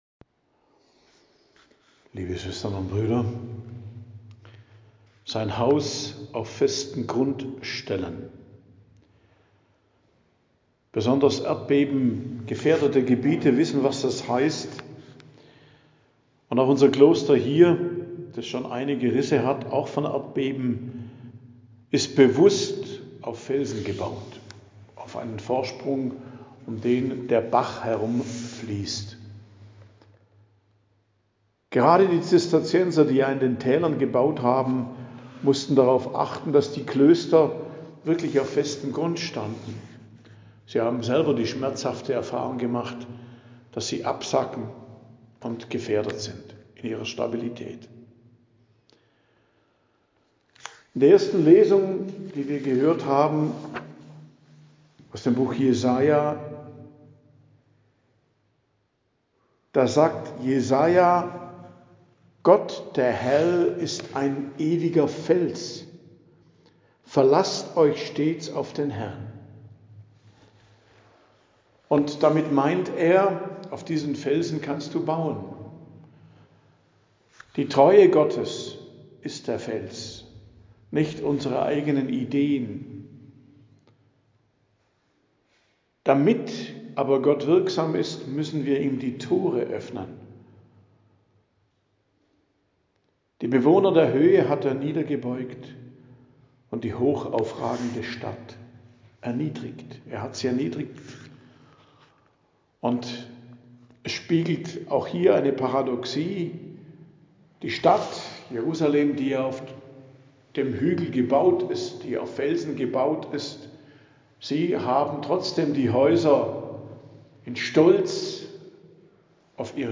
Predigt am Donnerstag der 1. Woche im Advent, 4.12.2025